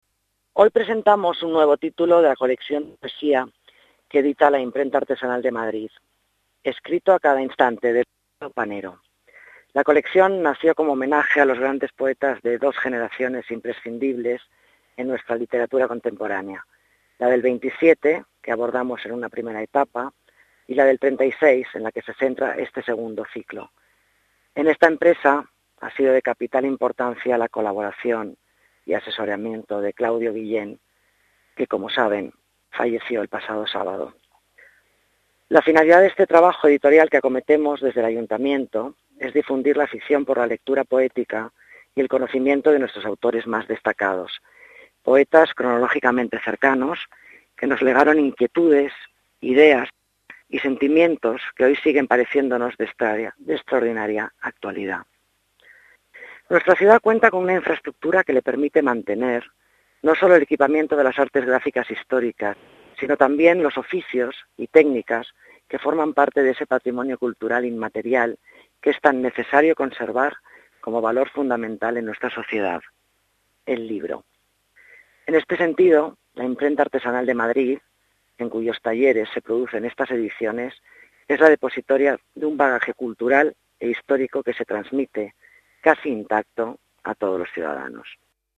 Nueva ventana:Declaraciones de Alicia Moreno, concejala de las Artes